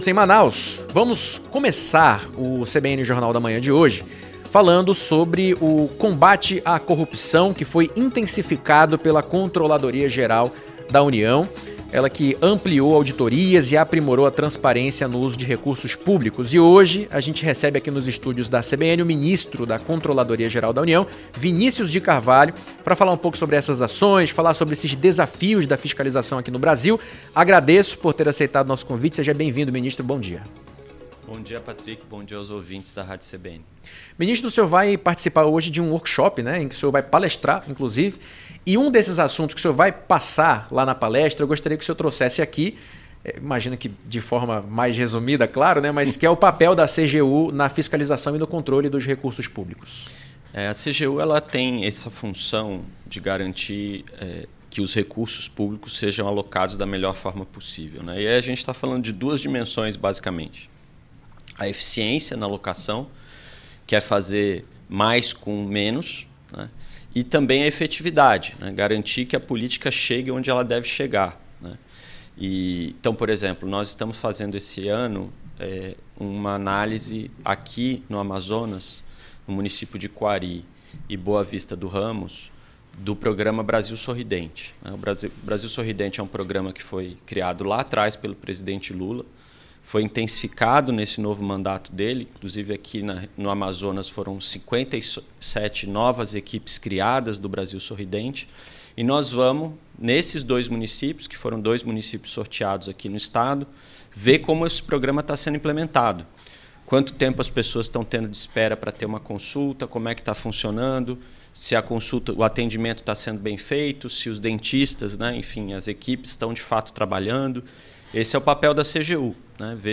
Em entrevista ao CBN Jornal da Manhã ministro fala das estratégias para ampliar ações contra corrupção e aprimorar acesso à informação